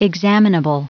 Prononciation du mot examinable en anglais (fichier audio)
Prononciation du mot : examinable